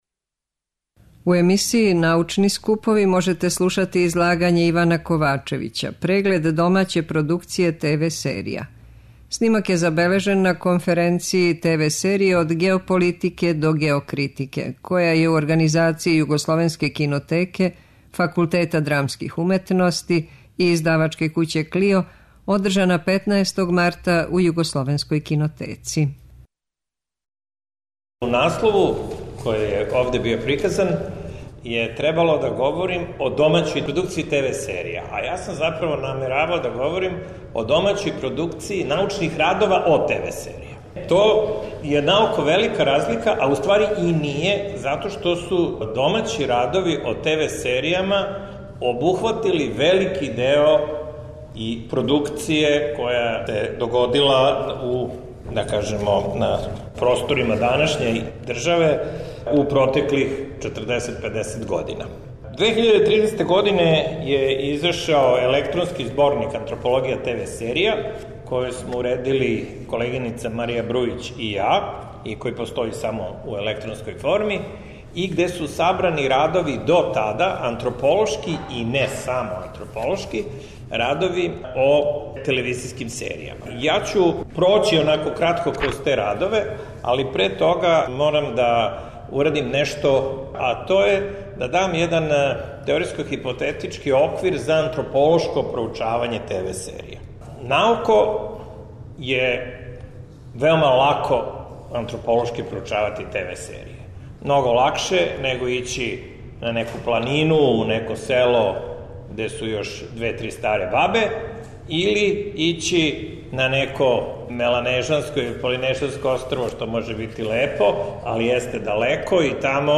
преузми : 10.76 MB Трибине и Научни скупови Autor: Редакција Преносимо излагања са научних конференција и трибина.